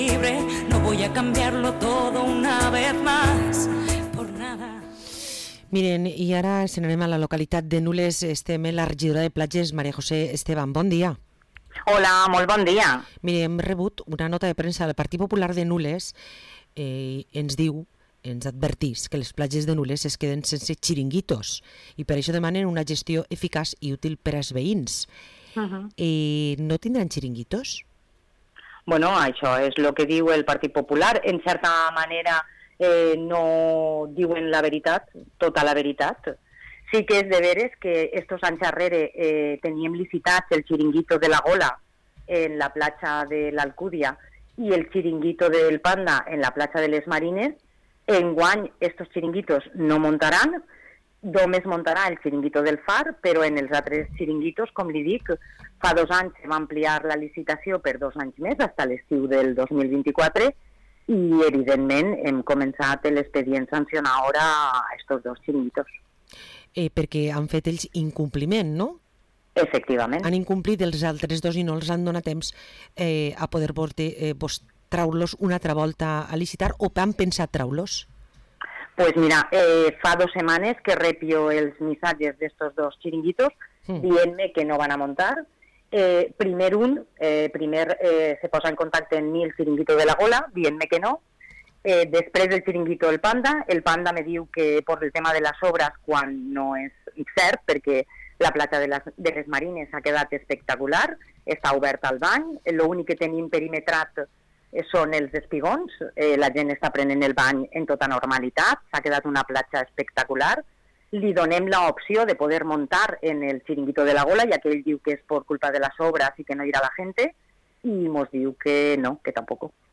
Entrevista a María José Estebán, regidora de platges a l´Ajuntament de Nules